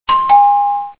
doorbell.wav